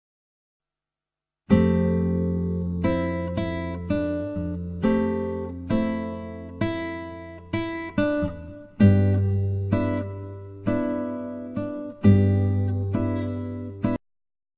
version réduite et compressée pour le Web